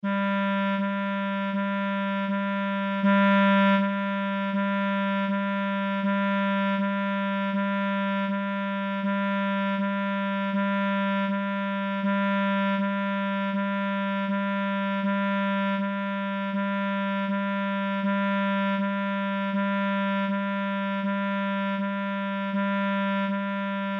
Nota SOL
A frecuencia da nota SOL é de 392 Hz.